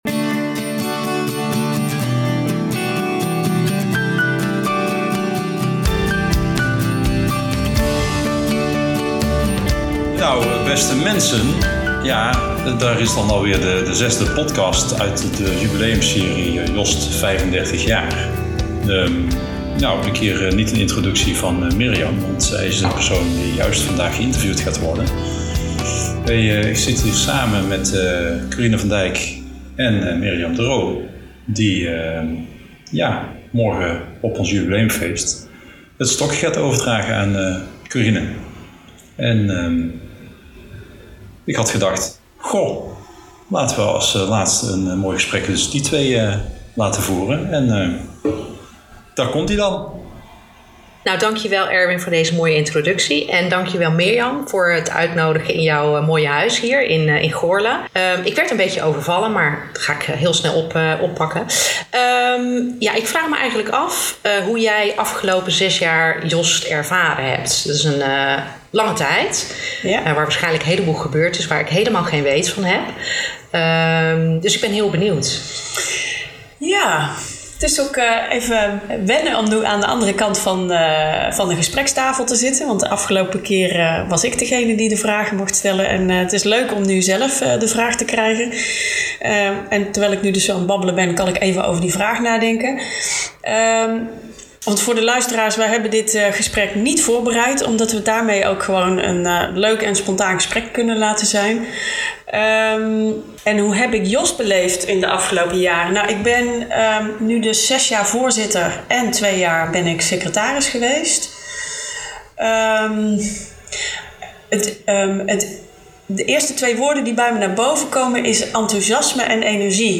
Het is een amicaal gesprek